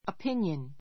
əpínjən オ ピ ニョン